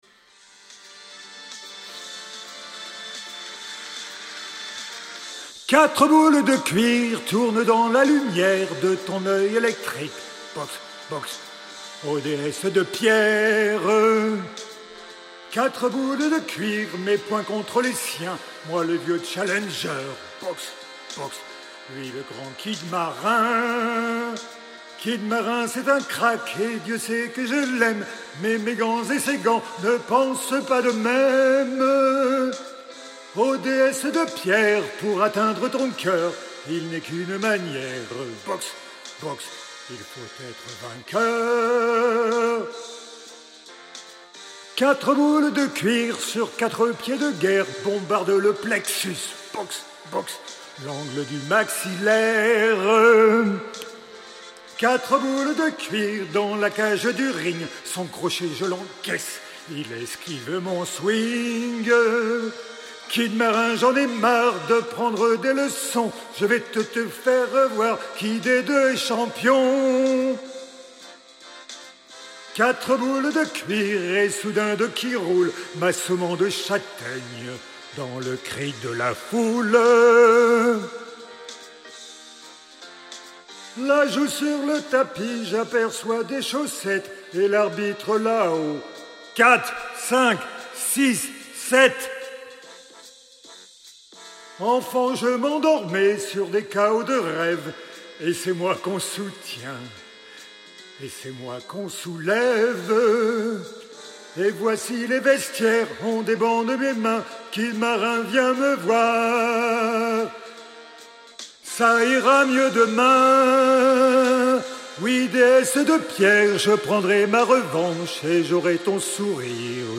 50 - 68 ans - Basse